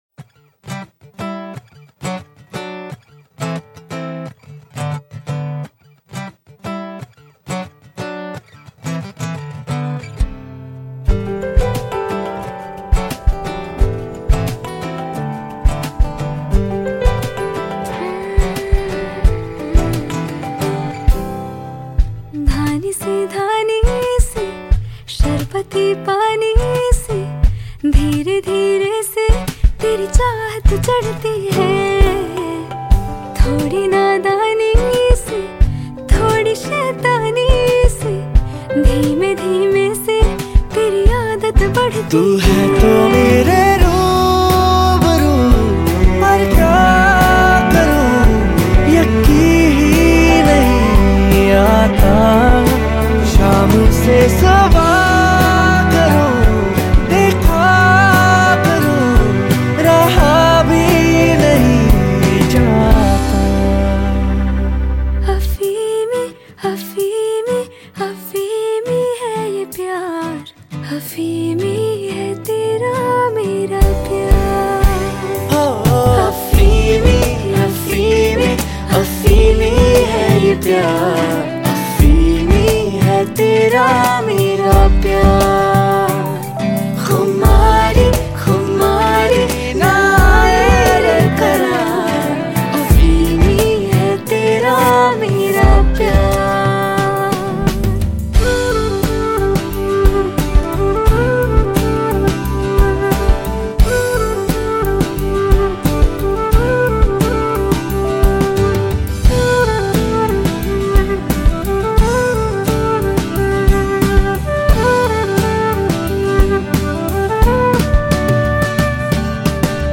popular Bollywood track